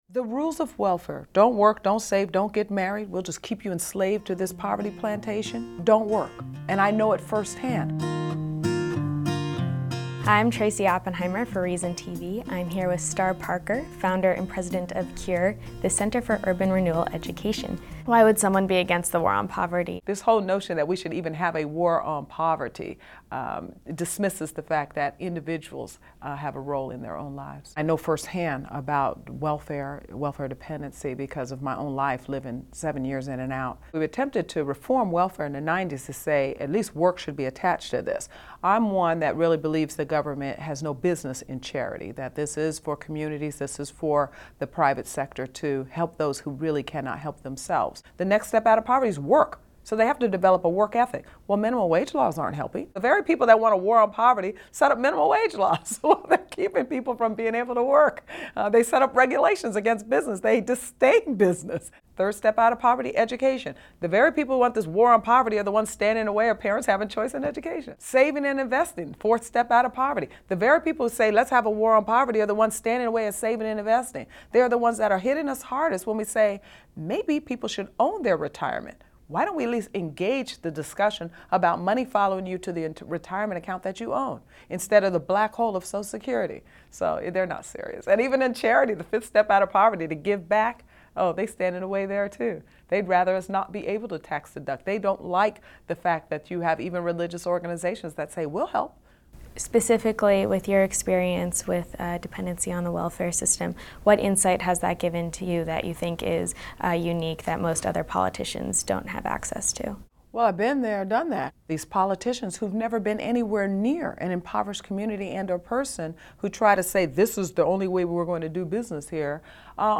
Get Government out of Welfare Now! An Interview with Star Parker